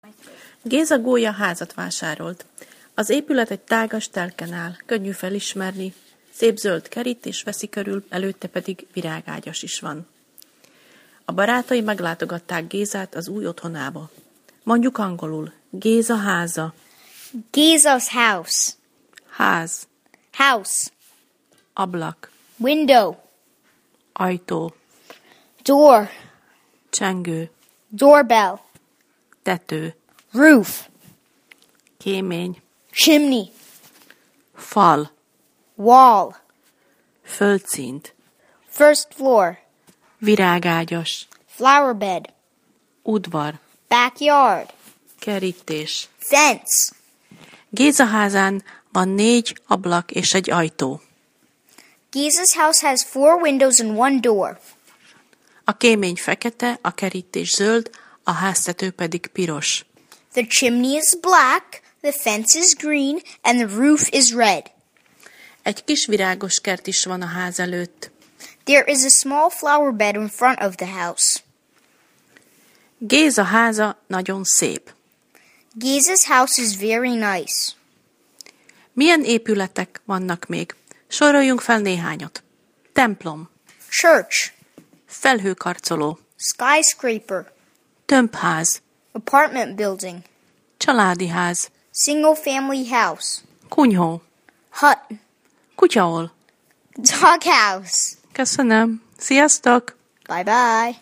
A hangos szótár eléréséhez kattints